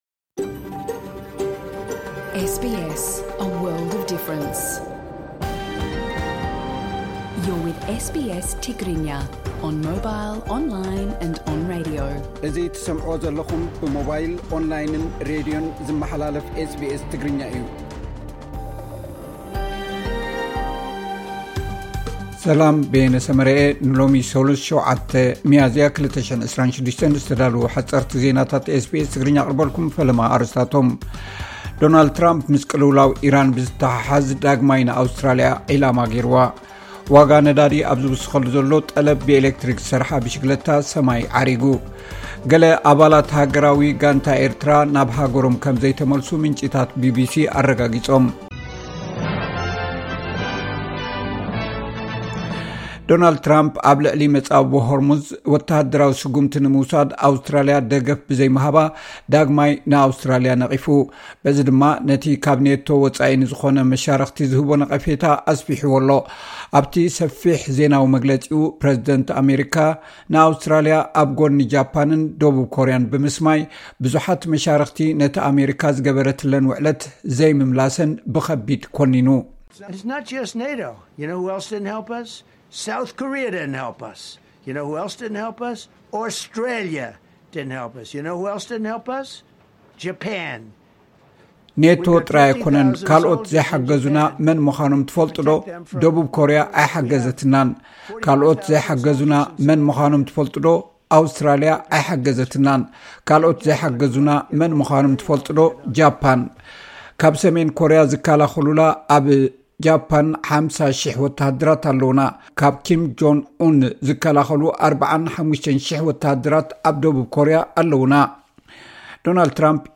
ሓጸርቲ ዜናታት SBS ትግርኛ (07 ሚያዝያ 2026)